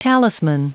Prononciation du mot talisman en anglais (fichier audio)
Prononciation du mot : talisman